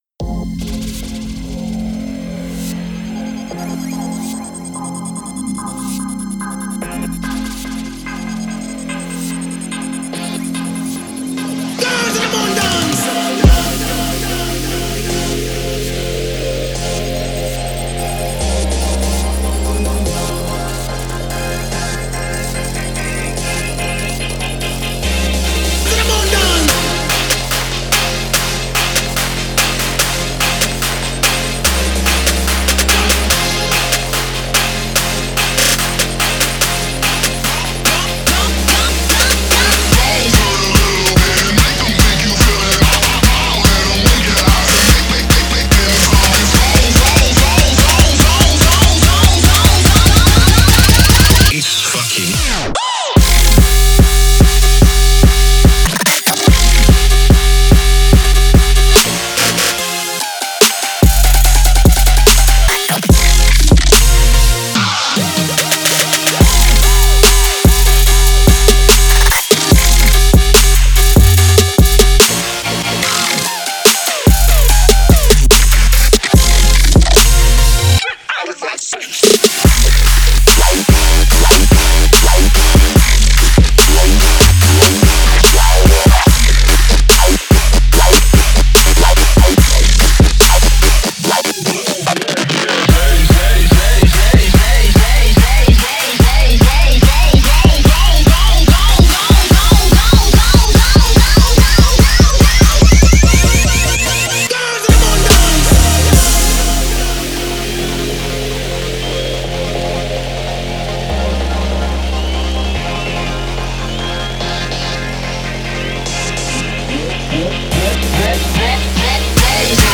چند آهنگ بیس دار مخصوص ماشین برای شما